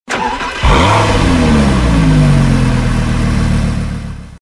Home gmod sound vehicles tdmcars r8v10
enginestart.mp3